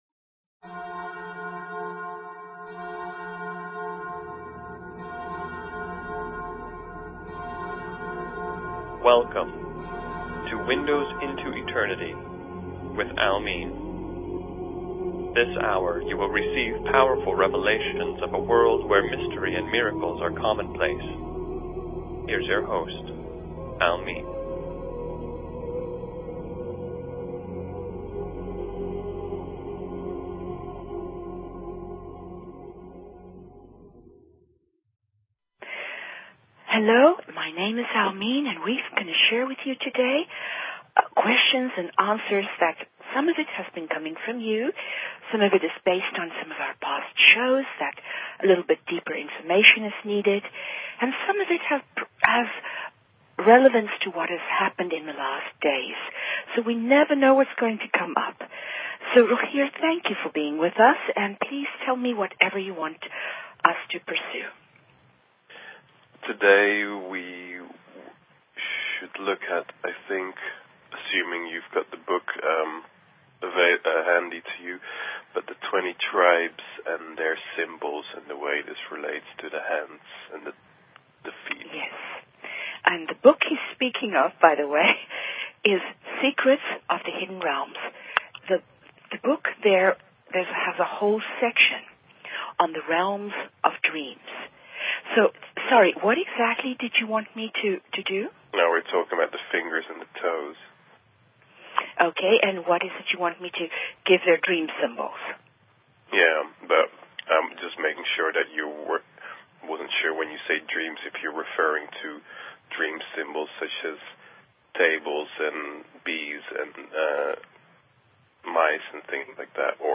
Talk Show Episode, Audio Podcast, Windows_Into_Eternity and Courtesy of BBS Radio on , show guests , about , categorized as